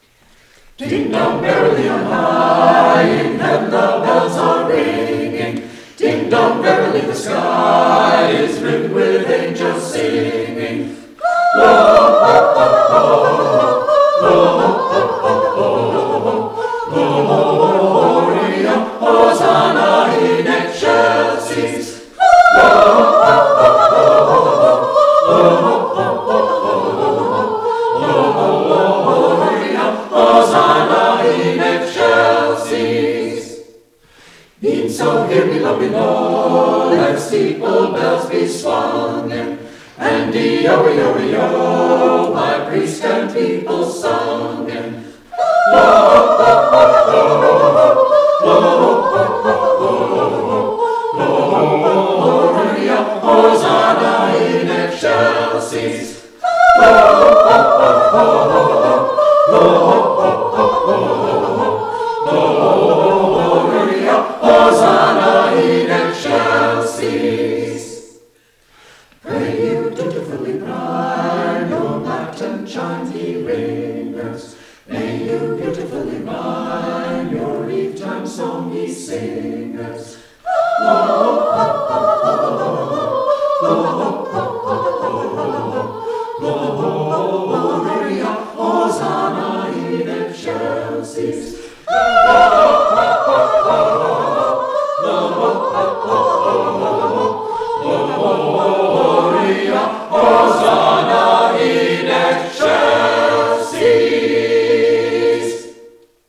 at the PubSing